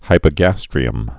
(hīpə-găstrē-əm)